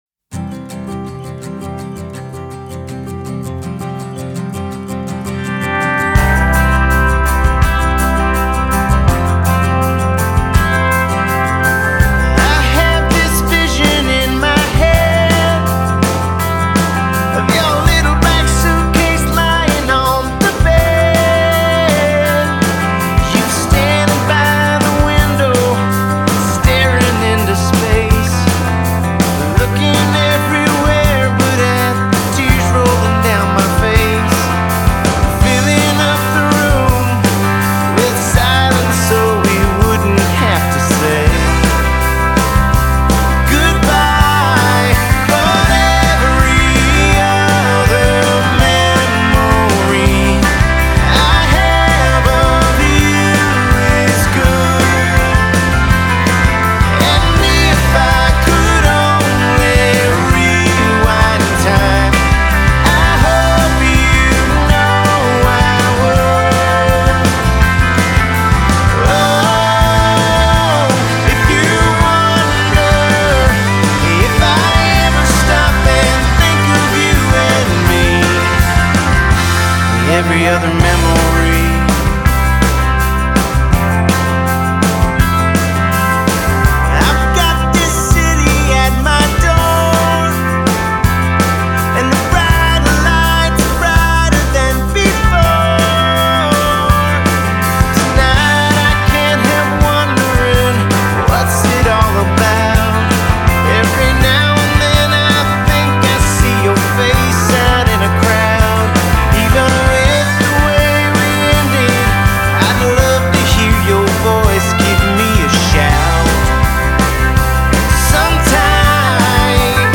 Genre: Country-Rock